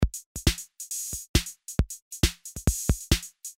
Triphop/dance/beat/hiphop/glitchhop/downtempo/chill
标签： chill loop trip electronic electro dance hop looppack sample glitch down tempo beat bass drum experimental instrumental Hip
声道立体声